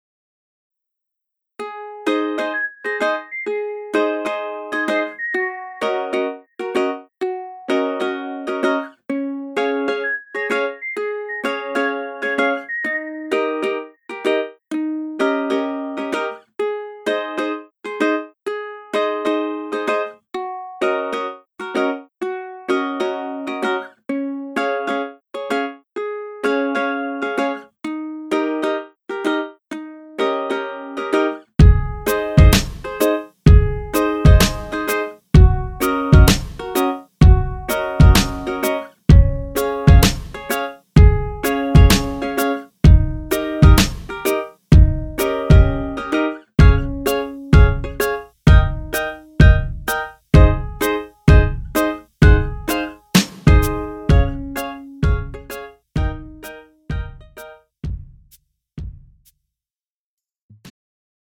음정 여자키 3:12
장르 가요 구분 Pro MR
Pro MR은 공연, 축가, 전문 커버 등에 적합한 고음질 반주입니다.